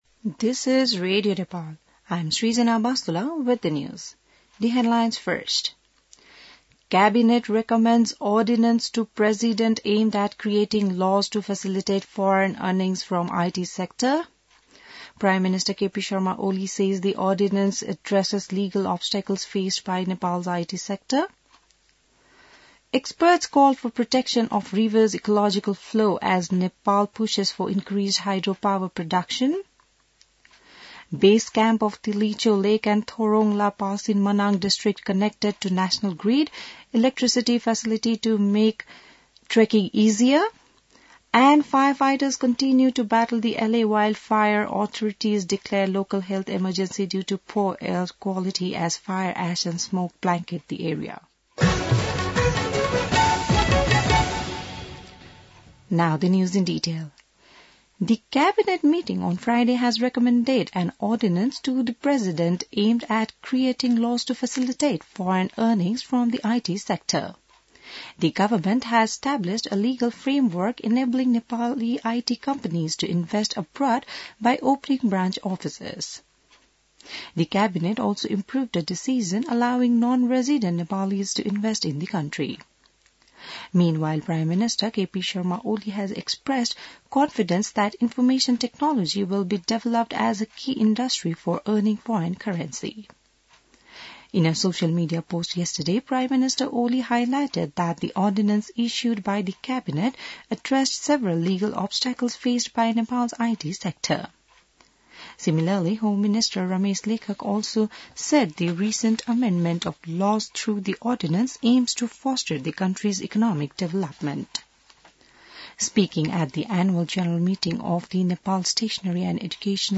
बिहान ८ बजेको अङ्ग्रेजी समाचार : २९ पुष , २०८१